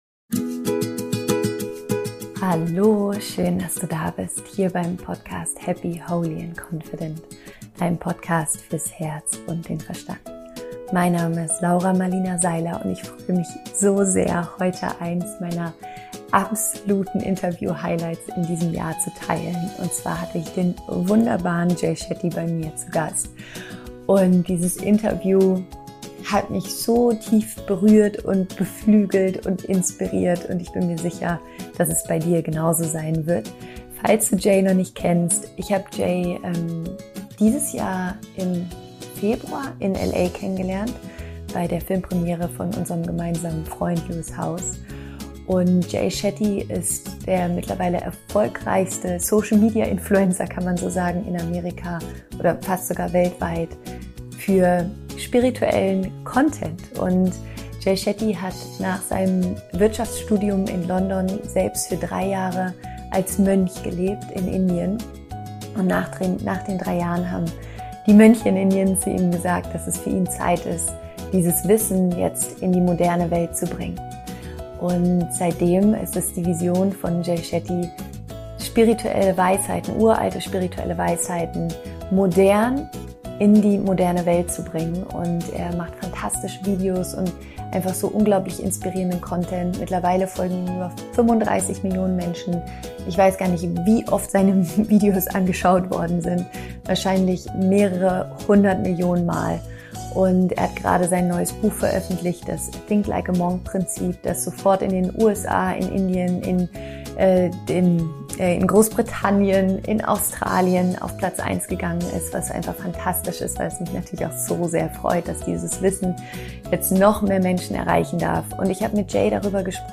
Ich freu mich so sehr, heute ein absolutes Interview Highlight mit dir teilen zu können: Jay Shetty war bei mir zu Gast! Nachdem Jay für 3 Jahre als Mönch gelebt hat, wurde er zu einem der erfolgreichsten Social Media Influencer, dessen Vision es ist, uralte spirituelle Weisheiten viral gehen zu lassen und Menschen zu inspirieren, ihre eigene Spiritualität zu entdecken.